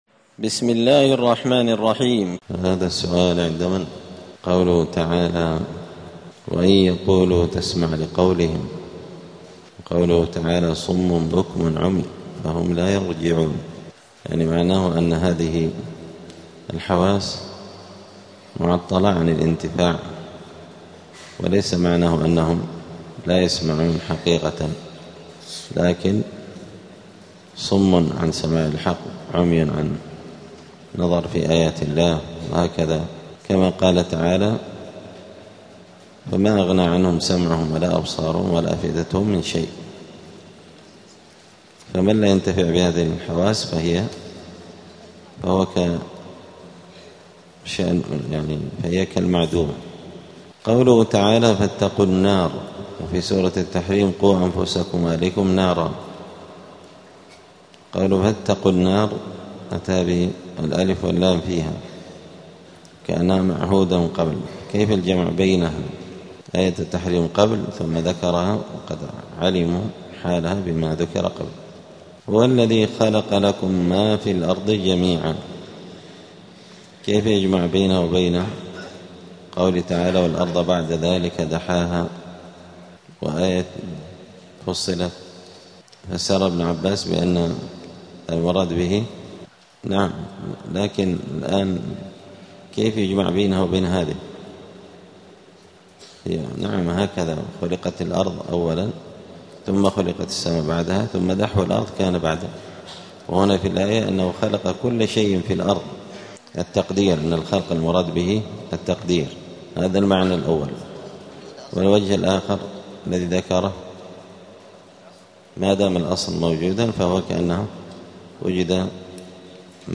*الدرس السادس (6) {سورة البقرة}.*